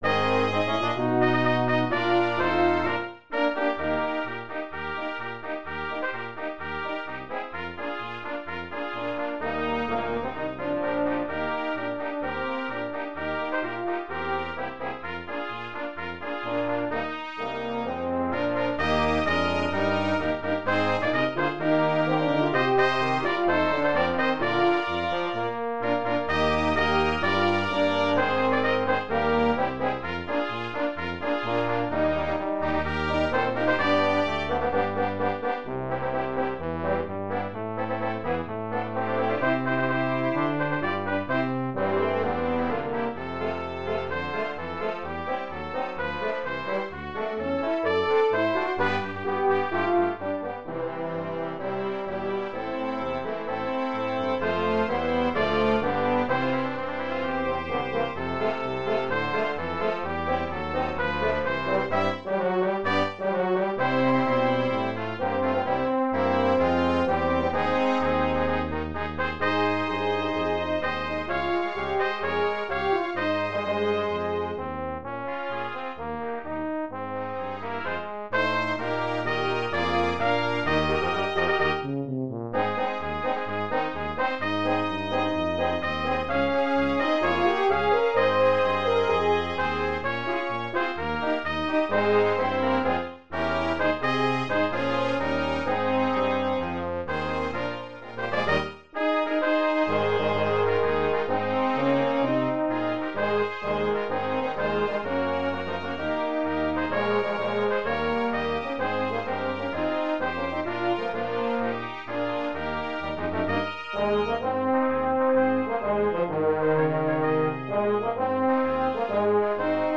Brass Quintet
Listen to a synthesized rendition of the whole piece.